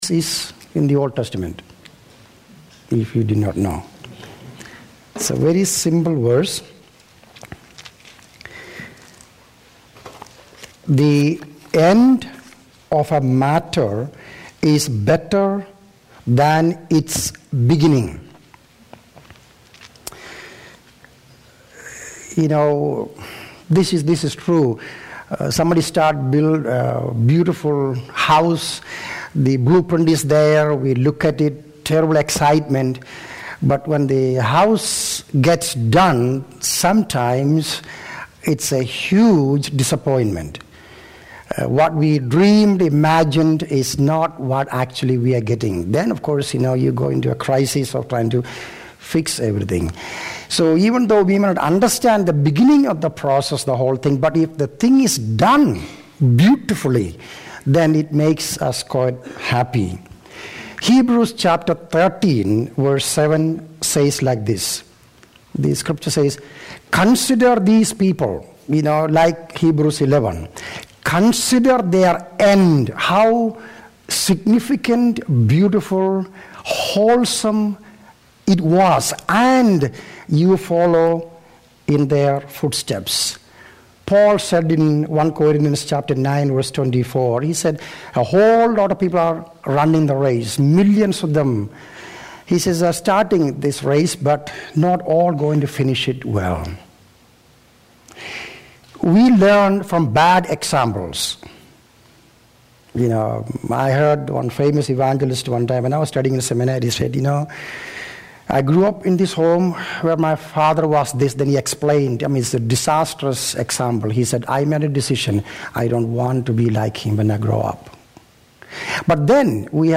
In this sermon, the speaker reflects on his own journey of serving God for over 43 years and the caution and fear he feels about his own spiritual journey. He emphasizes the importance of being aware of the deceptive nature of our hearts and the potential to lose focus. The speaker also highlights the story of Abraham, who made a choice to have total dependence on the Lord and ultimately finished his journey exceptionally well.